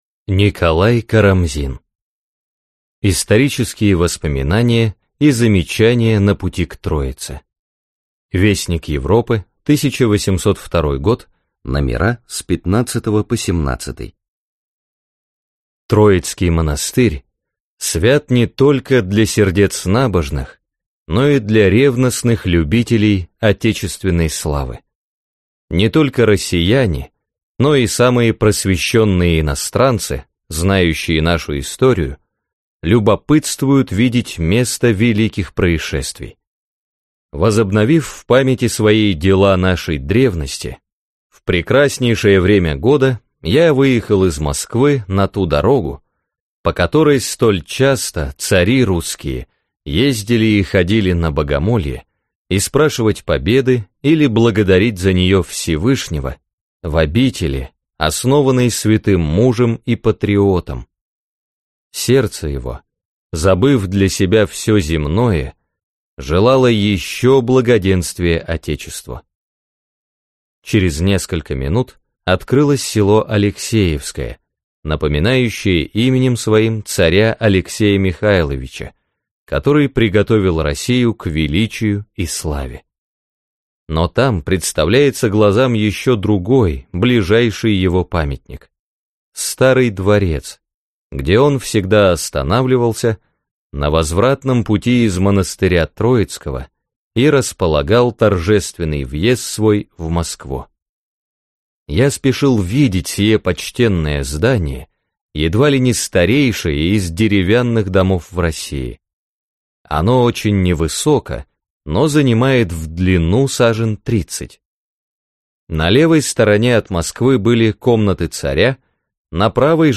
Аудиокнига Троице-Сергиева Лавра. К 700-летию со дня рождения преподобного Сергия Радонежского | Библиотека аудиокниг